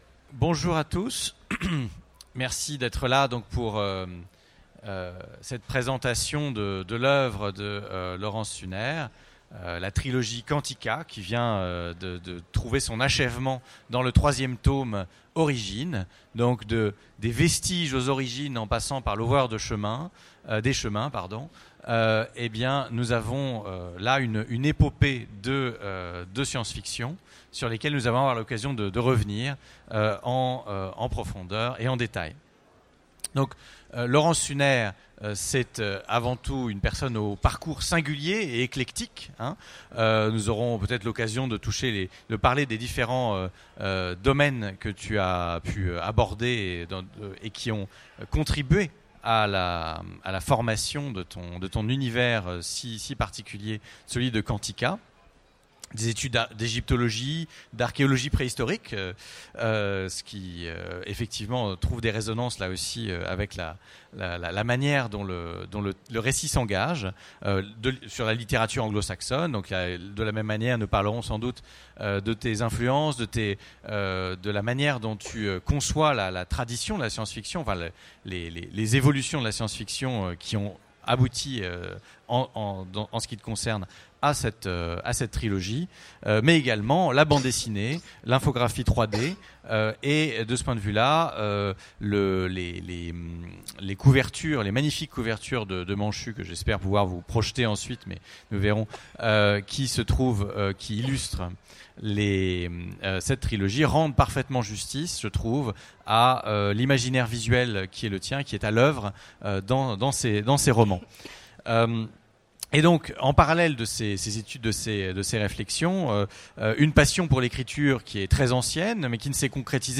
Mots-clés Rencontre avec un auteur Conférence Partager cet article